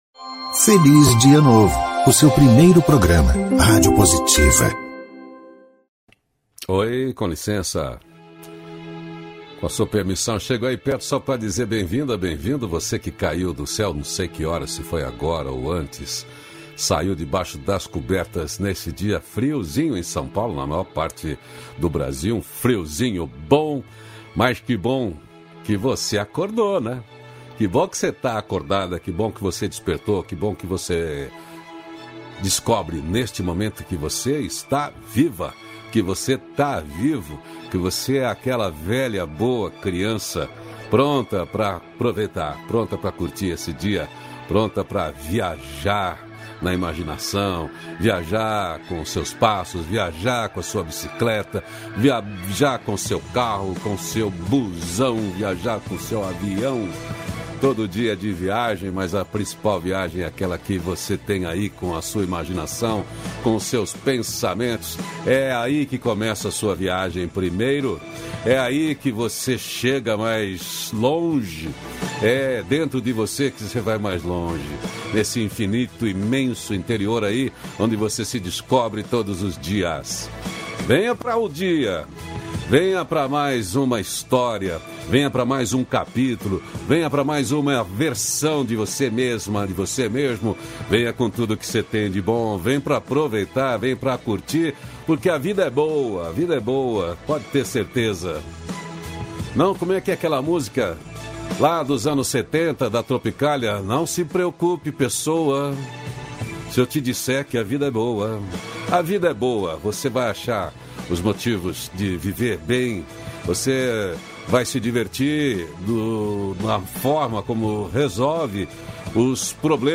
Diálogo Nutritivo
-496FelizDiaNovo-Entrevista.mp3